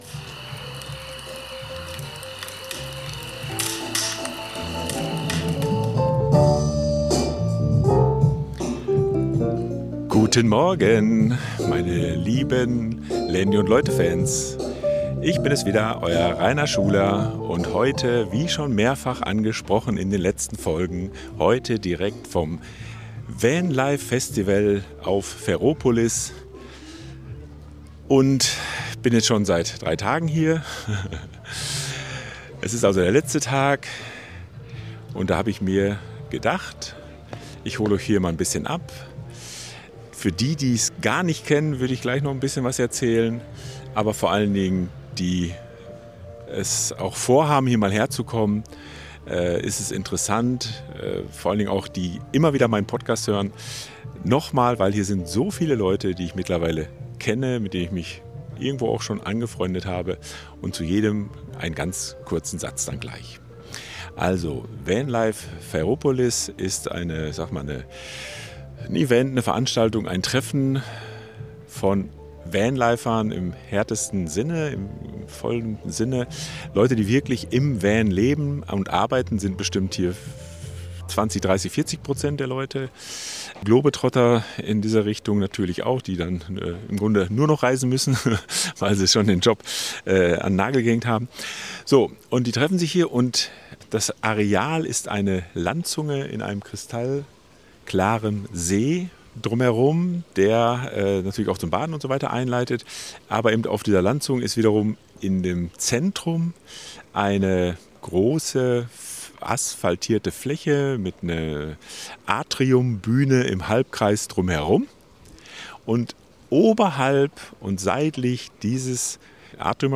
057 Live vom Vanlife Festival Ferropolis ~ Landy und Leute Podcast
Liebe Globetrotter, Festival-Genießer und Vanlifer, ich bin live beim Vanlife Festival Ferropolis vom Gremminer See bei Gräfenhainichen westlich von Dessau und nördlich von Leipzig.